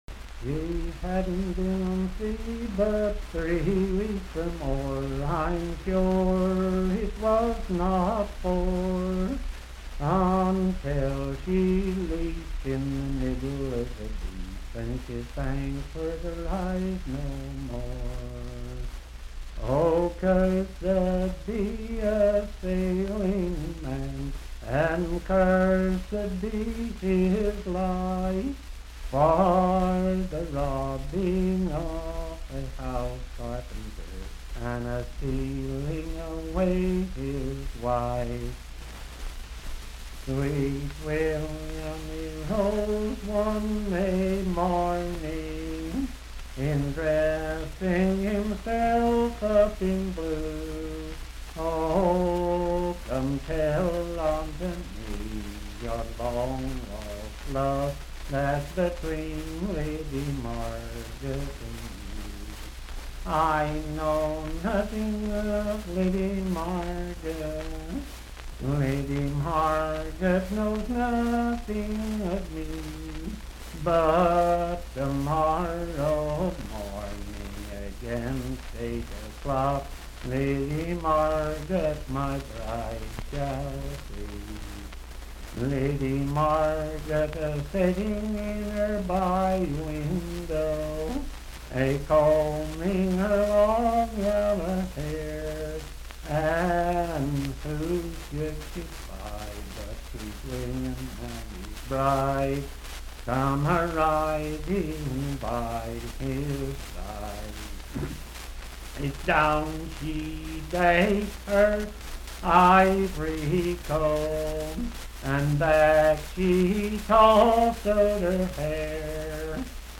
Unaccompanied vocal music
in Dryfork, WV
Verse-refrain 7(4).
Voice (sung)